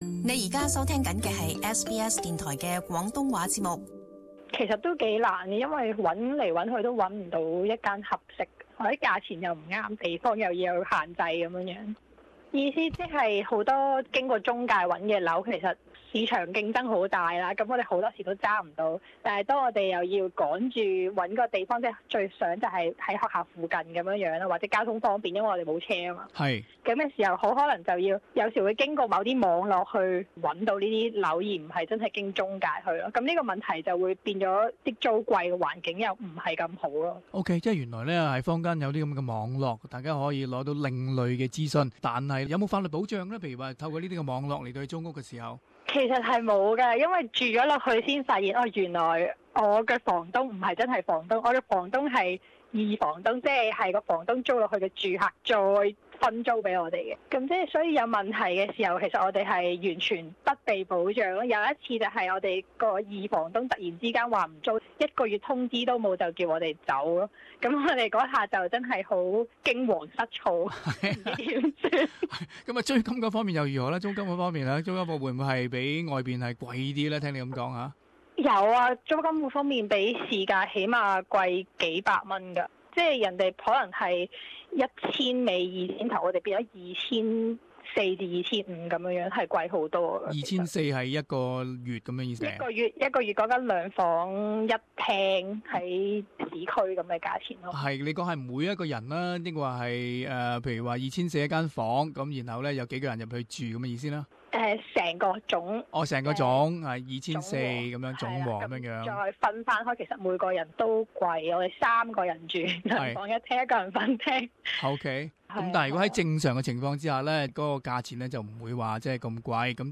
【社團專訪】外國留學生租住情況